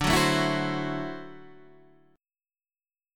D 9th